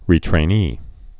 (rētrā-nē)